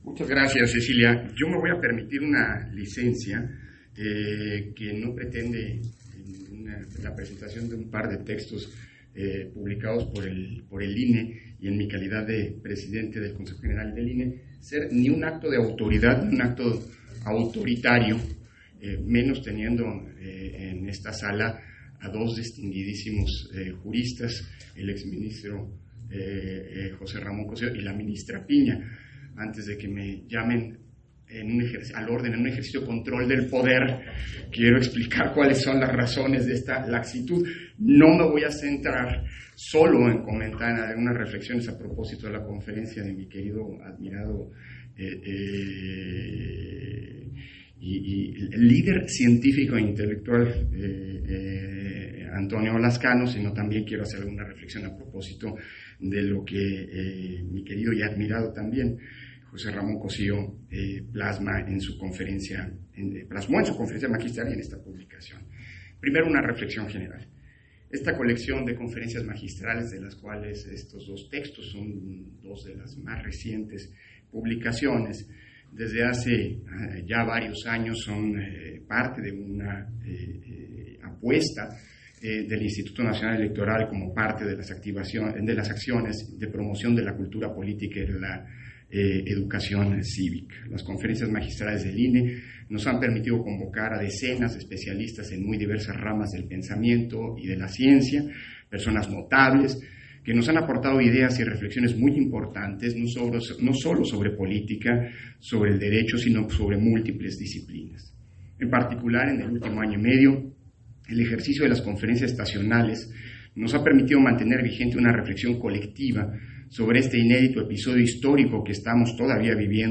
281121_AUDIO_INTERVENCIÓN-CONSEJERO-PDTE.-CÓRDOVA-PRESENTACIÓN-LIBROS-FIL - Central Electoral